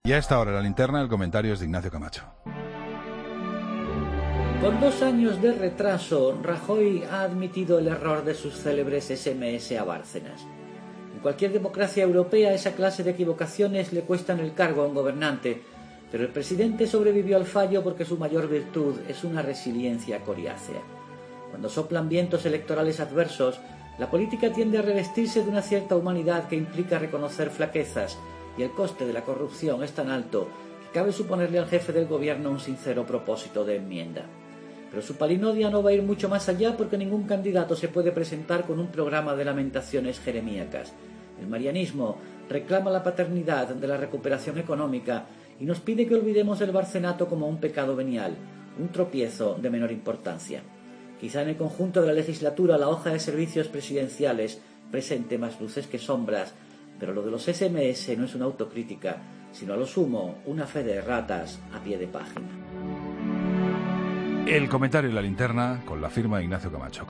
Ignacio Camacho dedica su comentario a Mariano Rajoy, quien admite el error de los SMS enviados a Bárcenas, un error que pudo costarle la presidencia y que se ha pasado por alto.